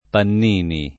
Pannini [ pann & ni ]